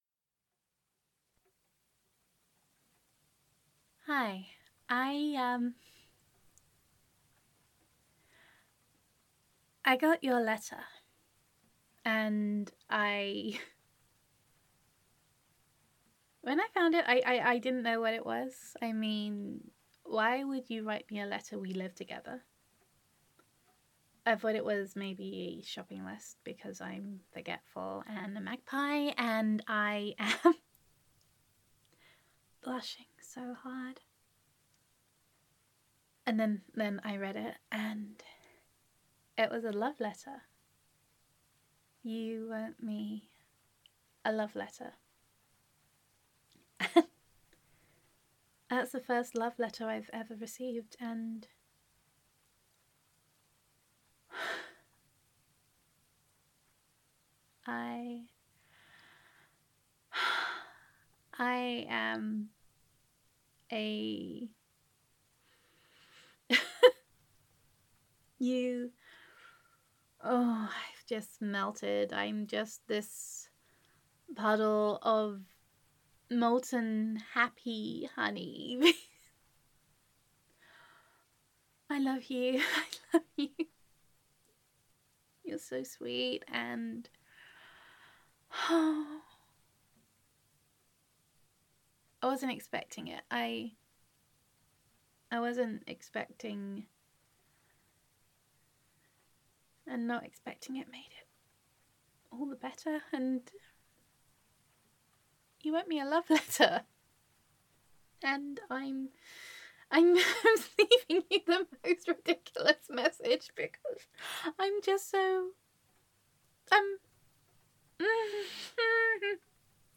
[F4A] I Read the Love Letter You Left Me [Overwhelmed with Happiness][Swooning][Gender Neutral][Girlfriend Voicemail]